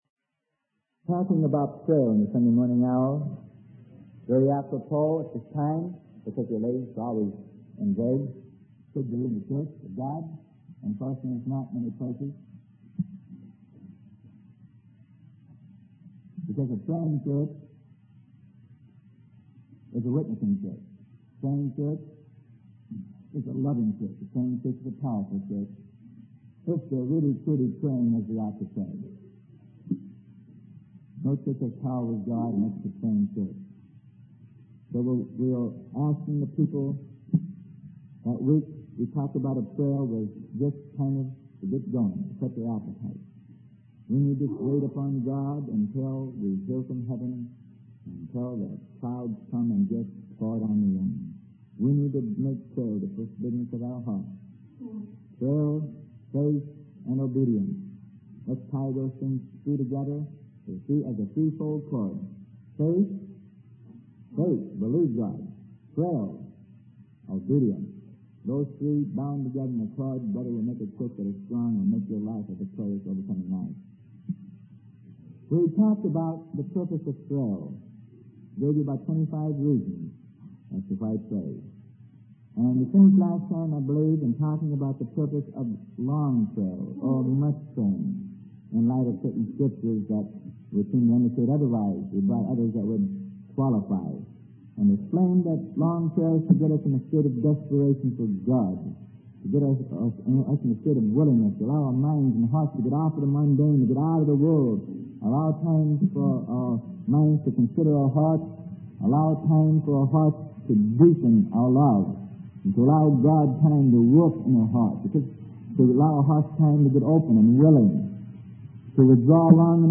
Sermon: How to be Led of God - Part 19 - Prayer - Freely Given Online Library